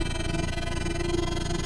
Speed_loop_1.ogg